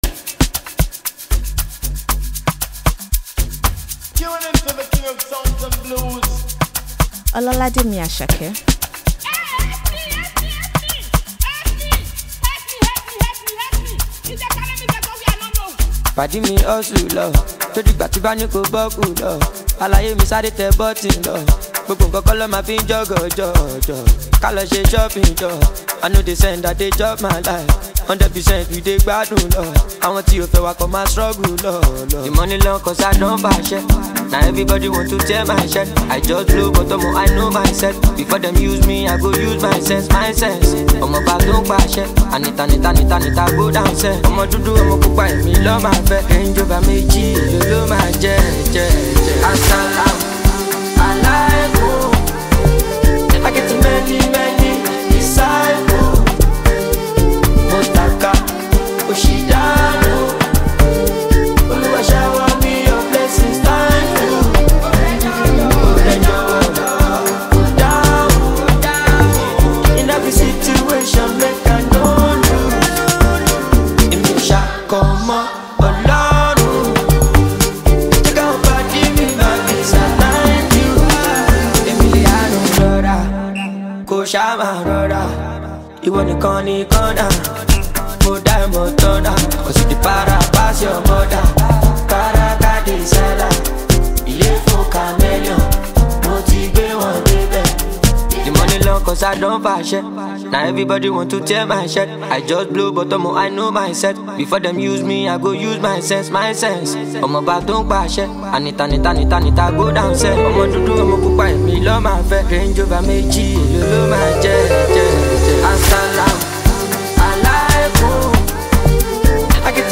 ” an interesting melody.